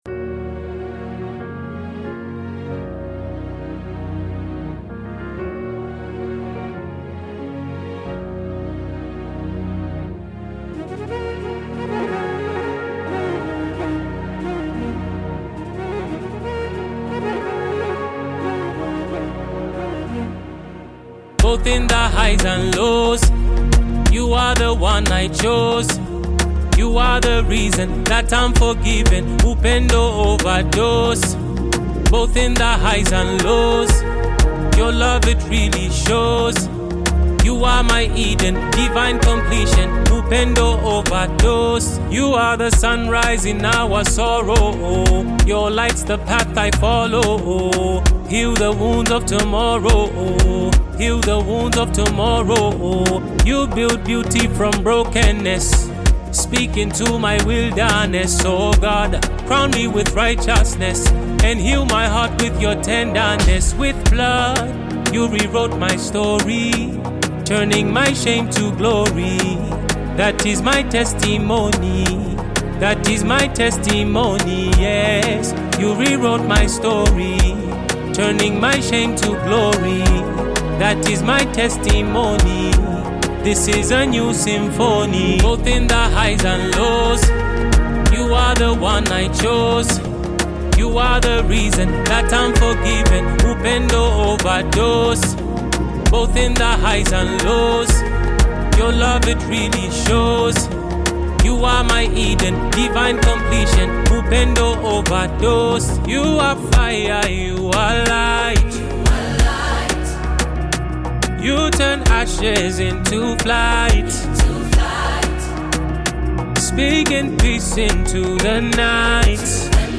rich instrumentation and uplifting melodies
vocal layering